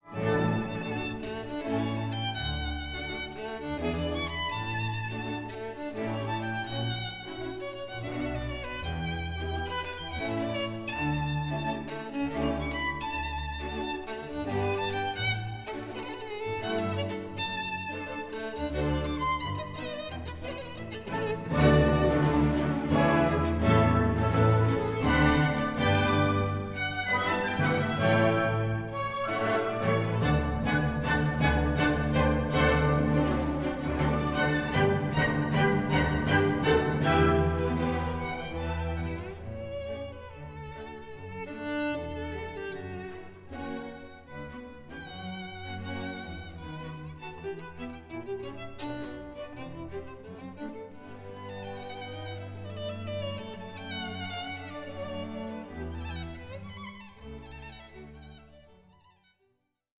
Lebhaft, doch nicht schnell 9:49